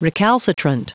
Prononciation du mot recalcitrant en anglais (fichier audio)